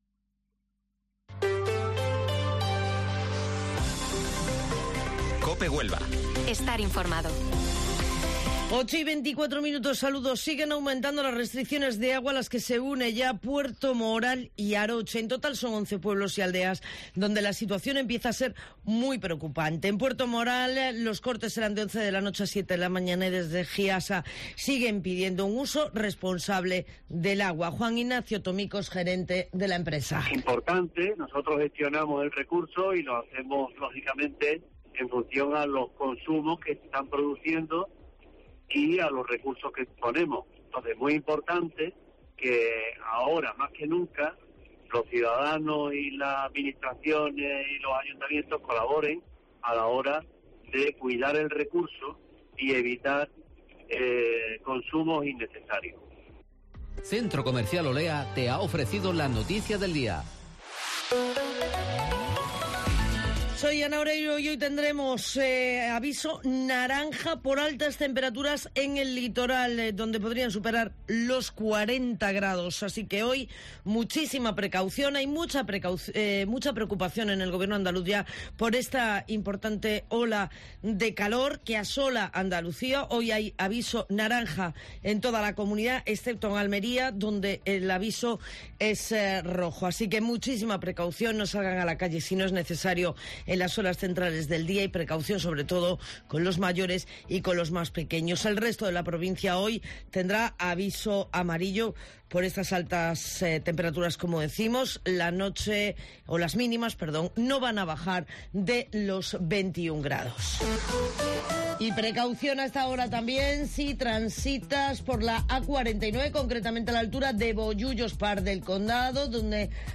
Informativo Matinal Herrera en COPE 12 de julio